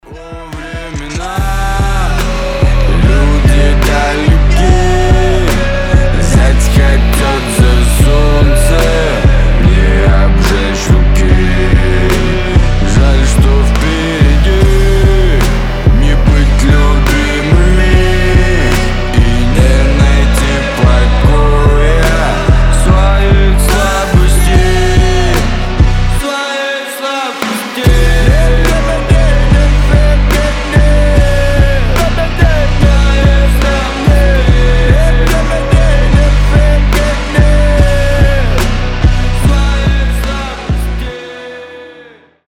• Качество: 320, Stereo
Хип-хоп
душевные
мощные басы